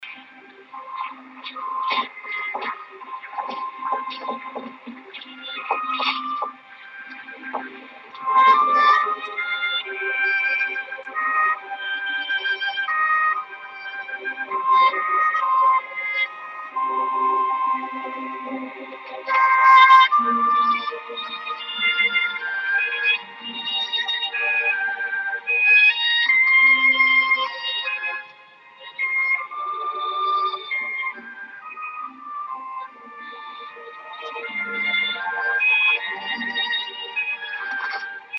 доброго дня, всем! услышал в фильме мелодию, как мог записал, что-то очень знакомое, вроде из классики, кто подскажет название